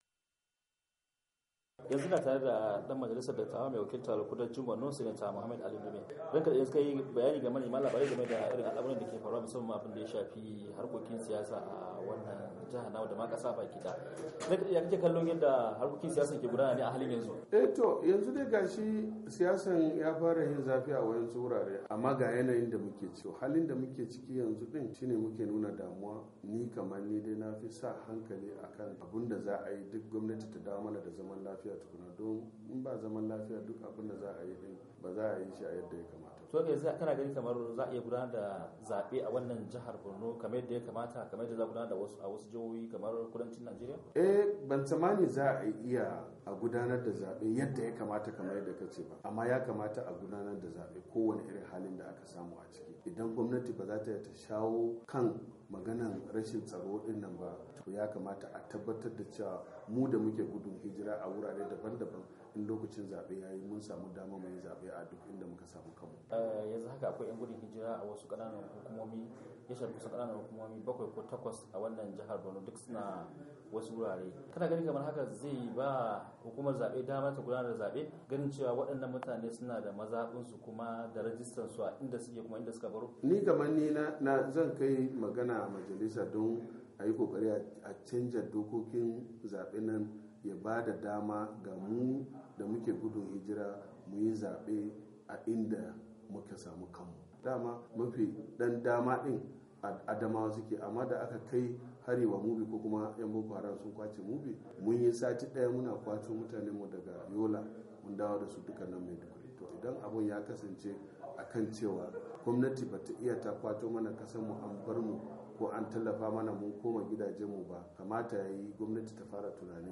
A wata fira da yayi da Muryar Amurka Sanata Ali Ndume mai wakiltar kudancin jihar Borno yace gwamnatin Shugaba Jonathan ba da gaske ta keyi ba akan yakar kungiyar Boko Haram.